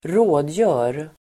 Ladda ner uttalet
Uttal: [²r'å:djö:r]